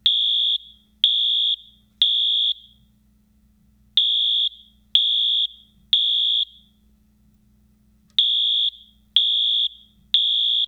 Life safety notification appliances
wheelock-code3.wav